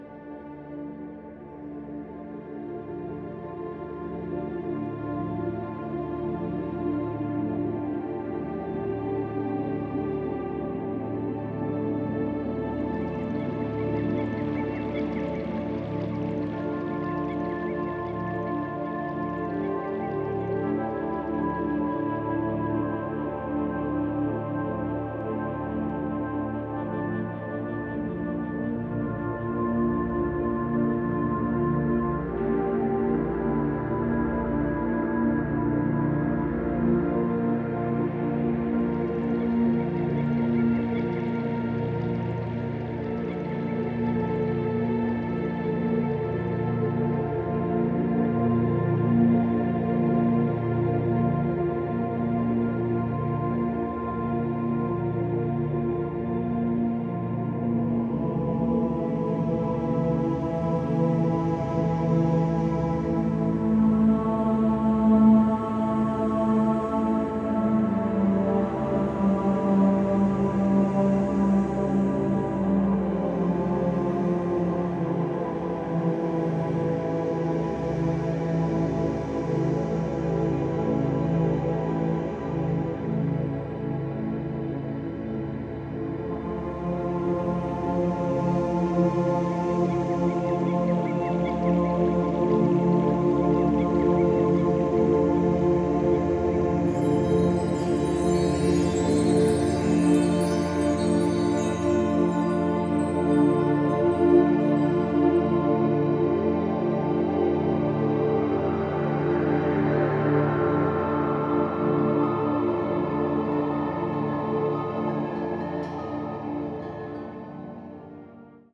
Infused with Binaural and Solfeggio 432 Hz frequencies.